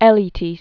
(ĕlē-tēs), Odysseus Pen name of Odysseus Alepoudelis. 1911-1996.